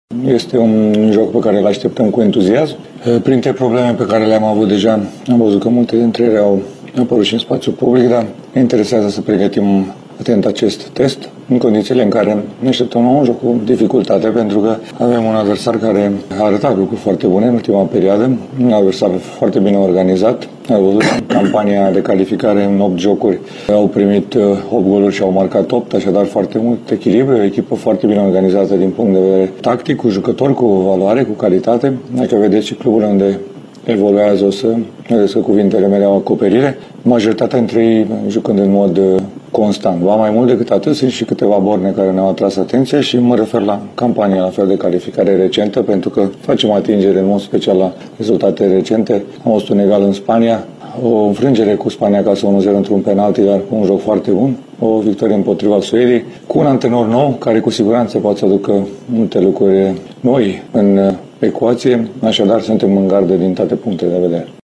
Antrenorul echipei naţionale de fotbal a României, Edward Iordănescu, a declarat, joi, într-o conferinţă de presă, că e în gardă din toate punctele de vedere în ceea ce priveşte debutul său ca selecţioner în partida amicală de vineri, cu Grecia.
Selecționerul a vorbit și despre adversar: